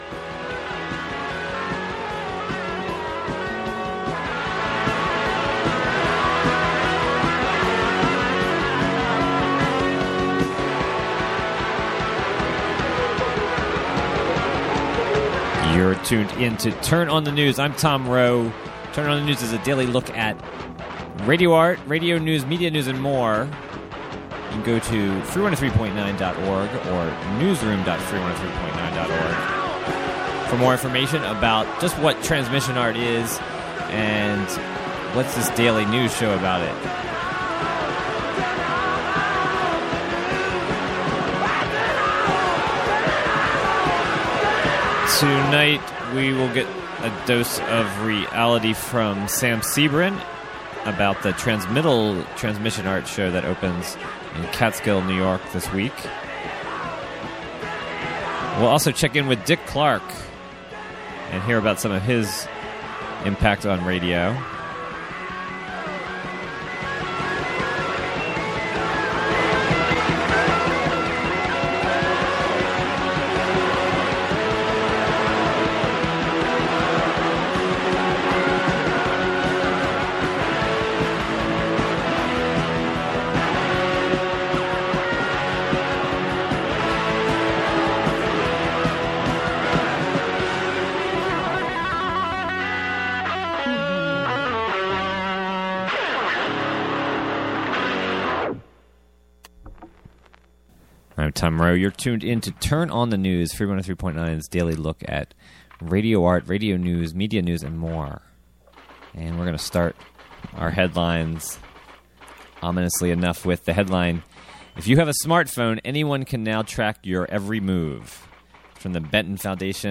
Turn On the News is a daily radio news program fea...